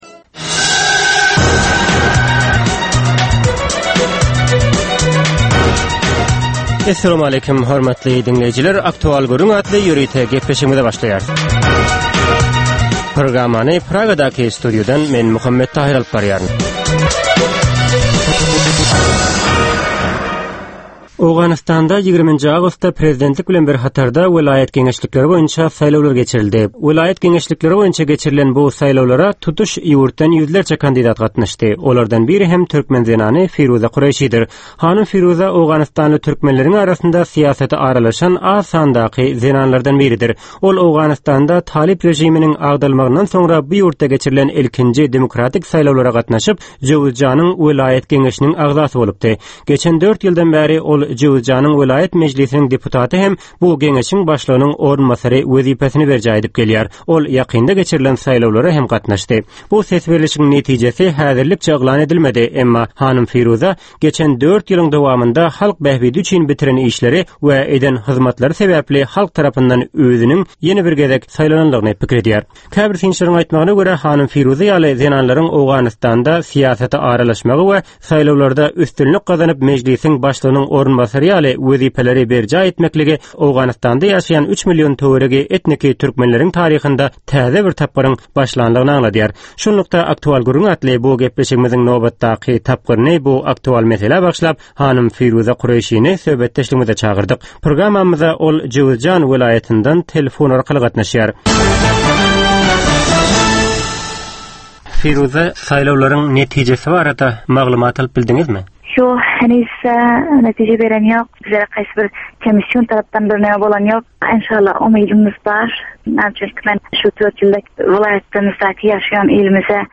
Hepdänin dowamynda Türkmenistanda ýa-da halkara arenasynda ýüze çykan, bolup geçen möhüm wakalar, meseleler barada anyk bir bilermen ýa-da synçy bilen geçirilýän 10 minutlyk ýörite söhbetdeslik. Bu söhbetdeslikde anyk bir waka ýa-da mesele barada synçy ýa-da bilermen bilen aktual gürründeslik geçirilýär we meselänin dürli ugurlary barada pikir alsylýar.